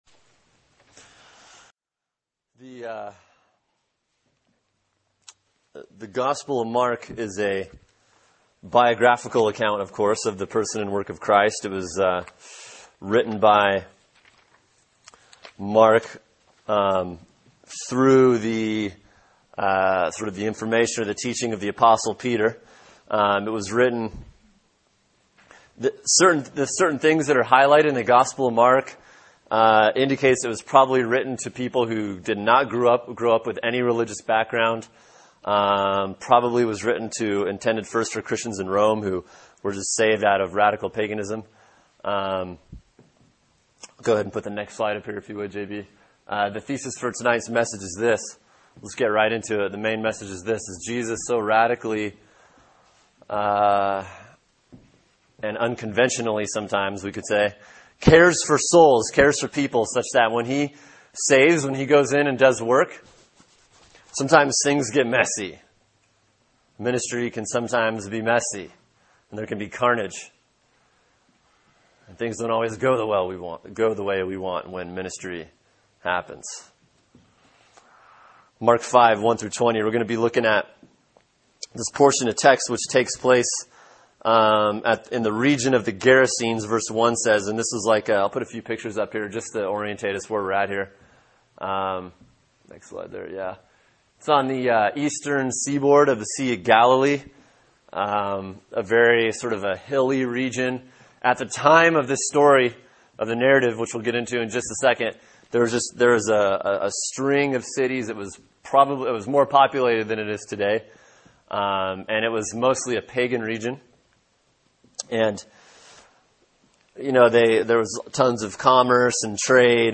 Sermon: Mark 5:1-20 “When Jesus Goes To Work” | Cornerstone Church - Jackson Hole